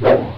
cpu_attack1.ogg